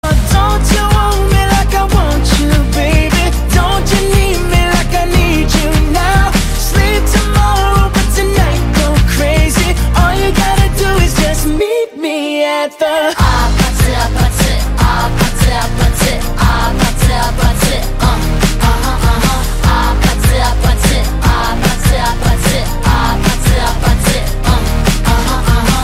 Kategorie POP